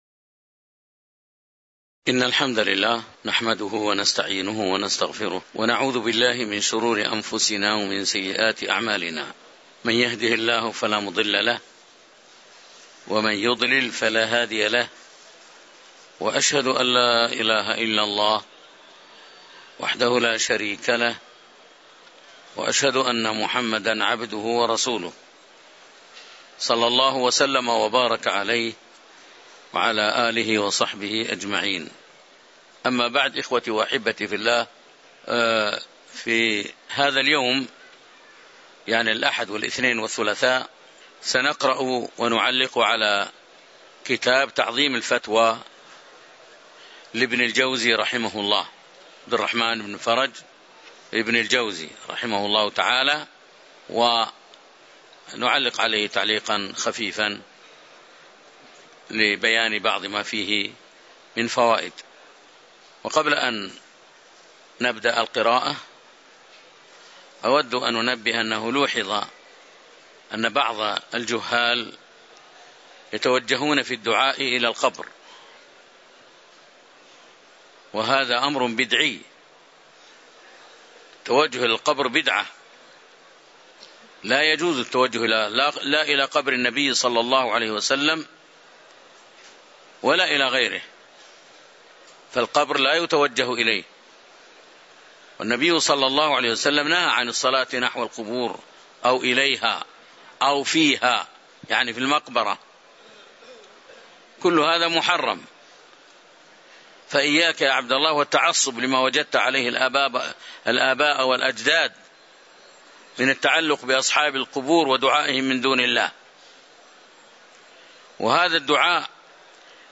تاريخ النشر ٨ جمادى الآخرة ١٤٤٤ المكان: المسجد النبوي الشيخ